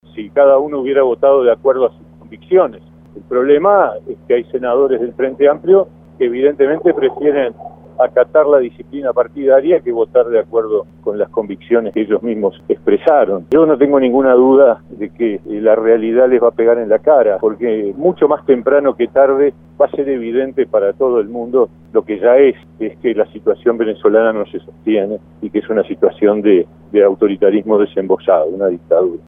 Mieres dijo a Radio El Espectador que esta decisión del oficialismo le pegará en la cara ya que en el Senado había votos para condenar el fallo.